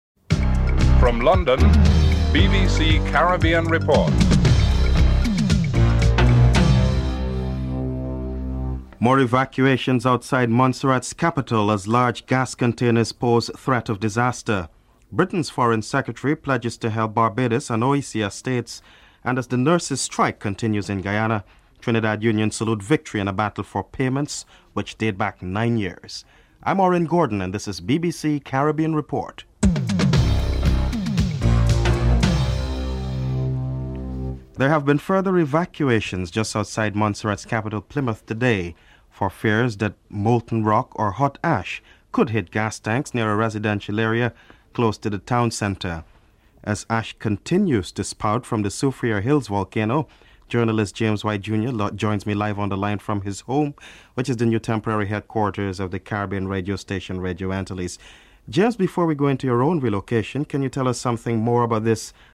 1. Headlines (00:00-00:33)
Minister of Foreign and Commonwealth Affairs Malcolm Rifkind and Prime Minister Vaughn Lewis are interviewed (07:59-10:00)